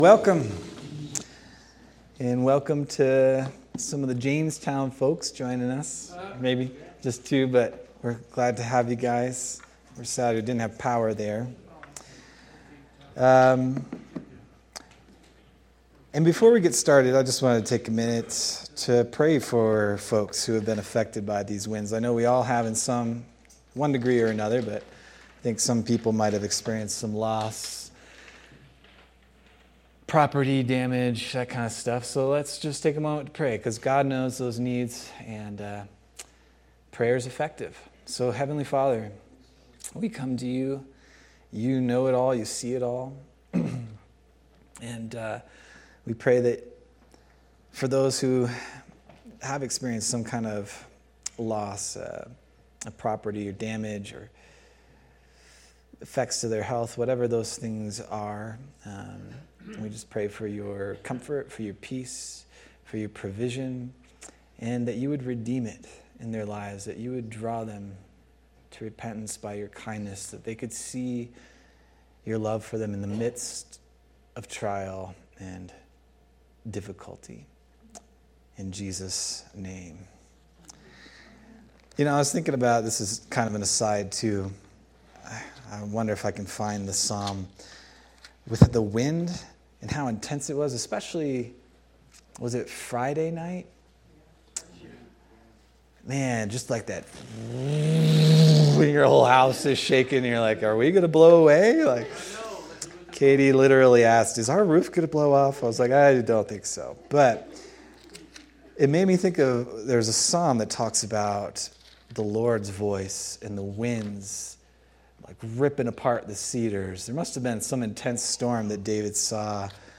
December 21st, 2025 Sermon